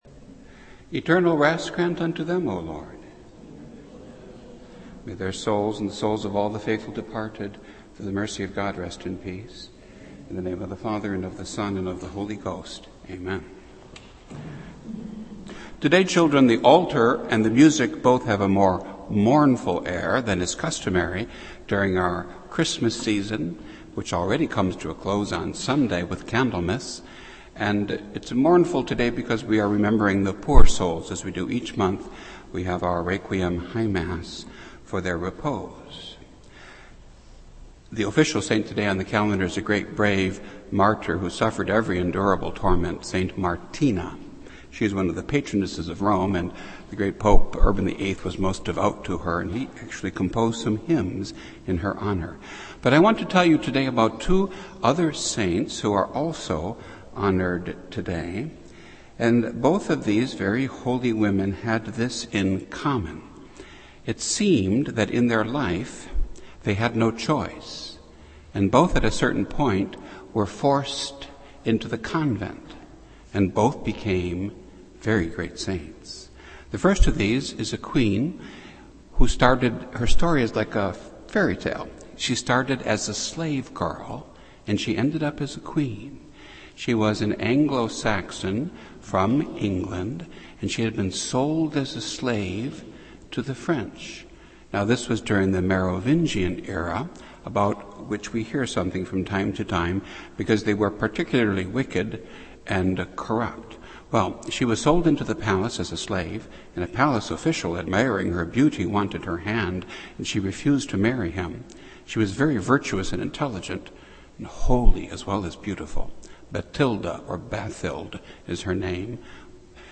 This entry was posted on Thursday, January 30th, 2014 at 11:00 pm and is filed under Sermons.